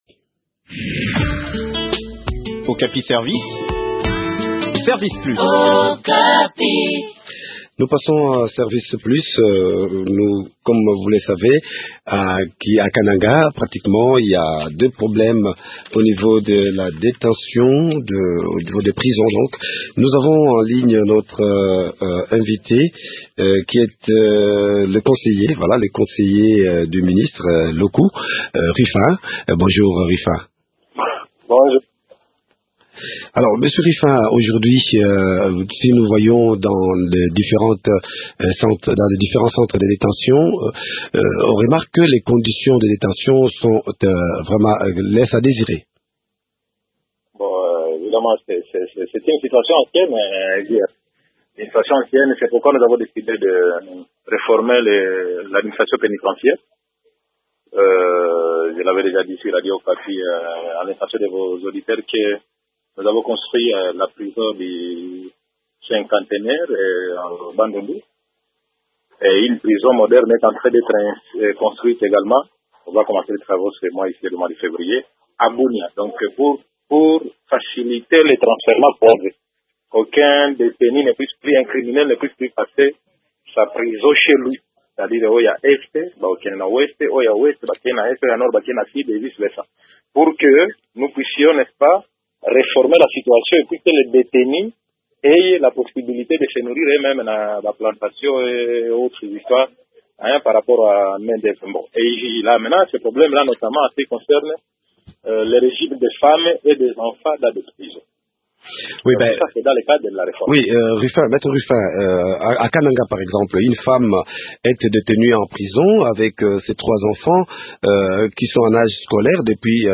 Le point du sujet dans cet entretien